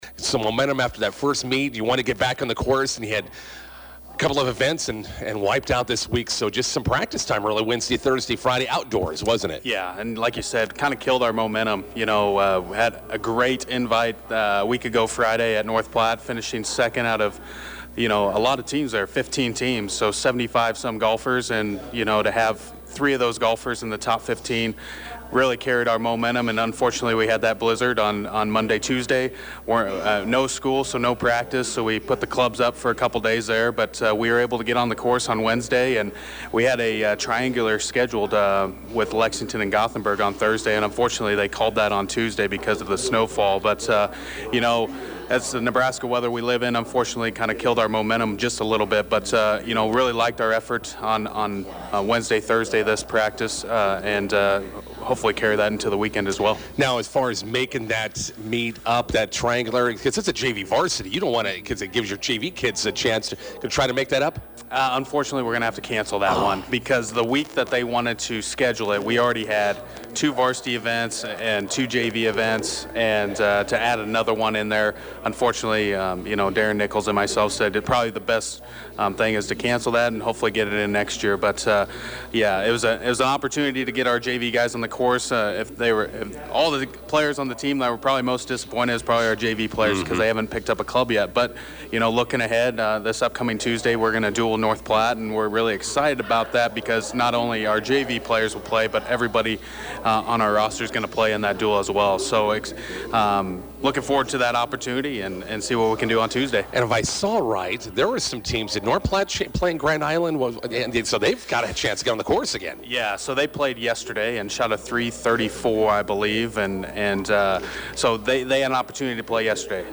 INTERVIEW: Bison golfers back on the course today, hosting North Platte at Heritage Hills.